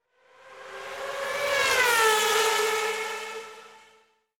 • スーパーカー通過90年代のエンジンサウンド